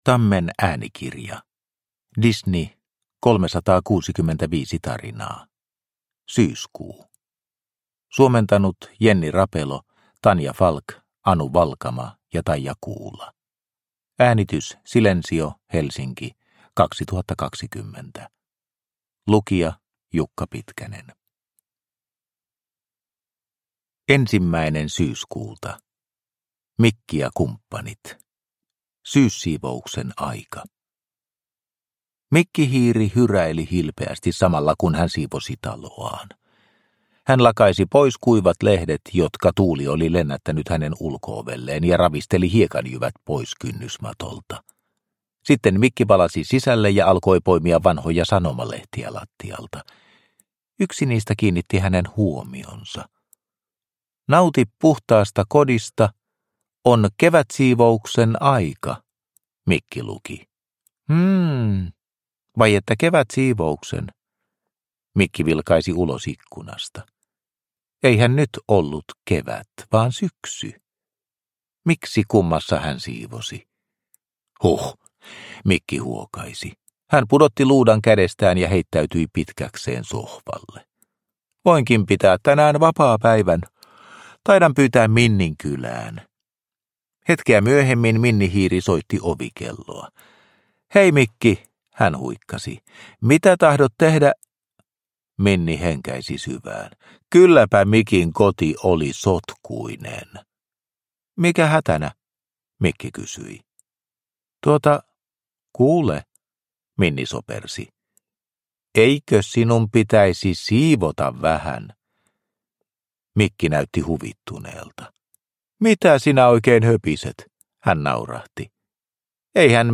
Produkttyp: Digitala böcker